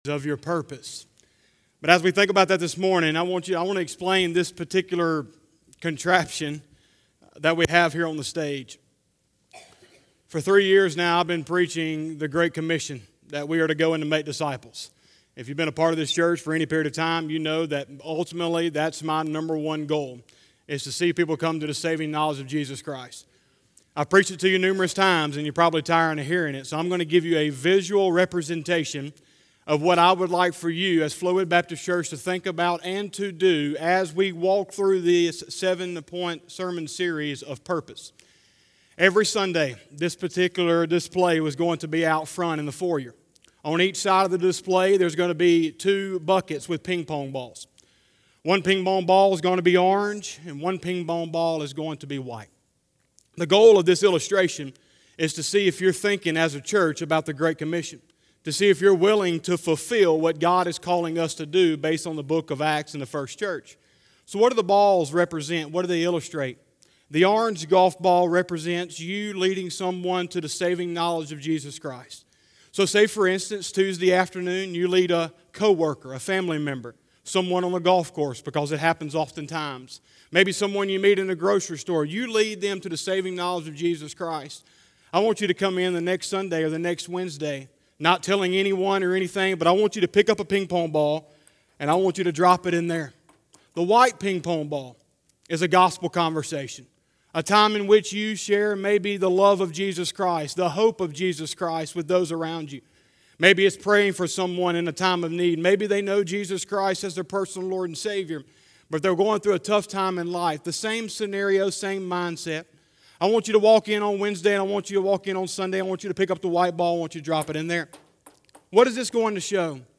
09/06/2020 – Sunday Morning Service